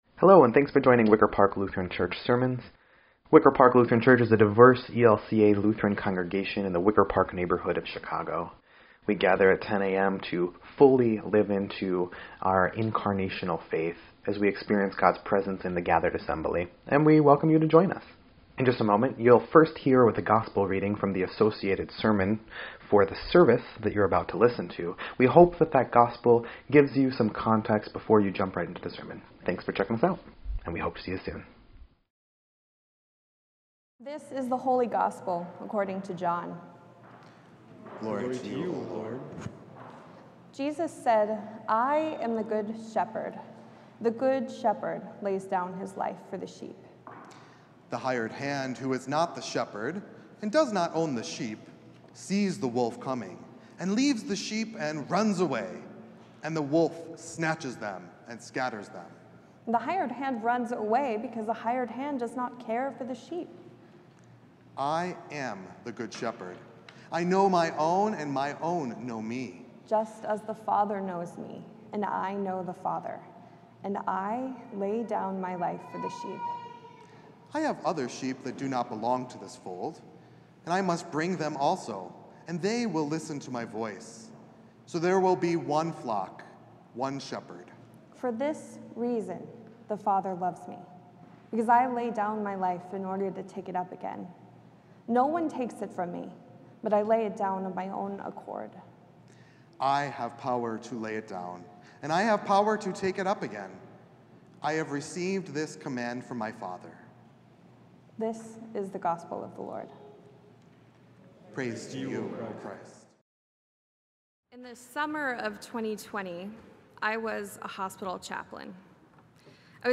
4.21.24-Sermon_EDIT.mp3